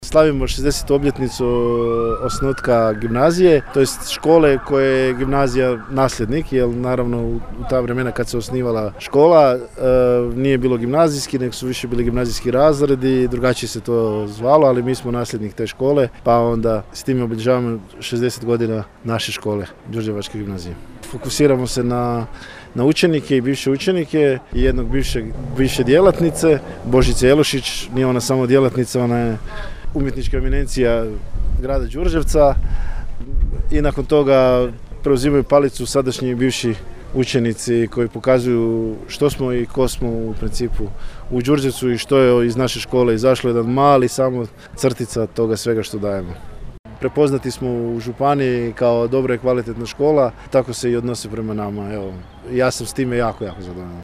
U Domu kulture u Đurđevcu prigodnim je programom održana proslava 60. godišnjice postojanja Gimnazije dr. Ivana Kranjčeva.